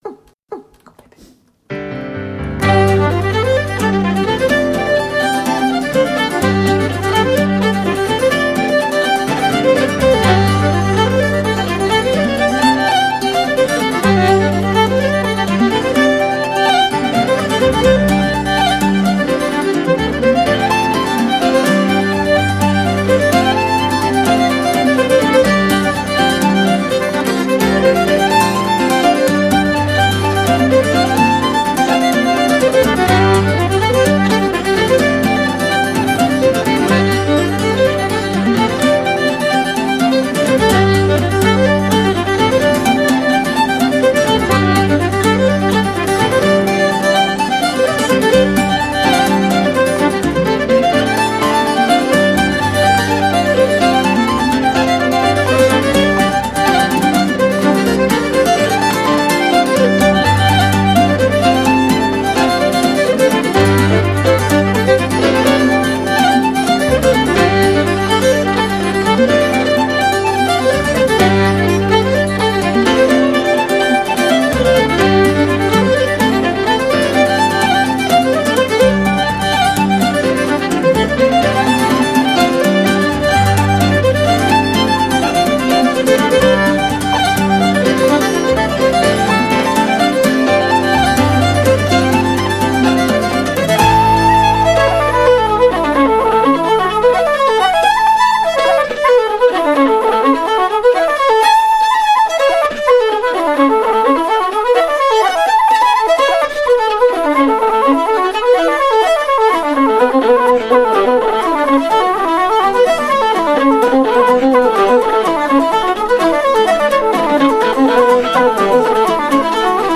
Koncert
gosli, kitara, vokal
mala harmonika, piščali
buzuki
flavta, piščali, klavir, vokal
mlada, domiselna in energična skupina iz Irske